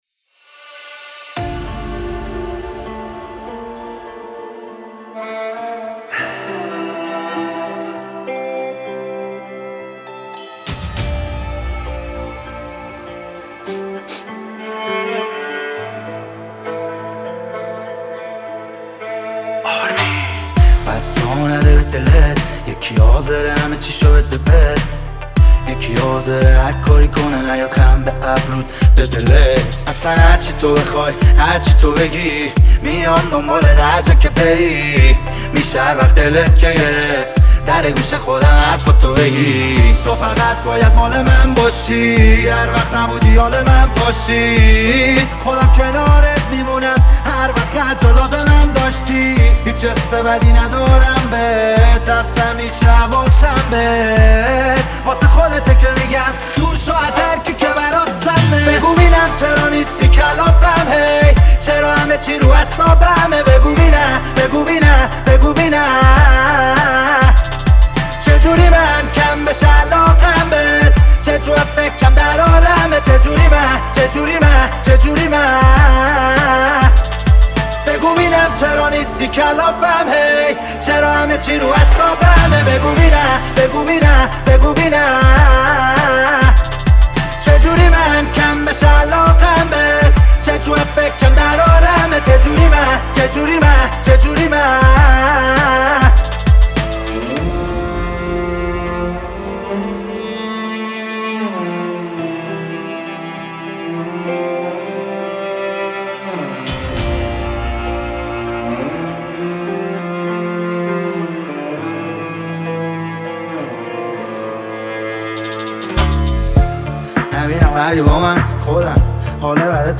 اثری عاشقانه با فضایی دلنشین است
با صدای گرم این خواننده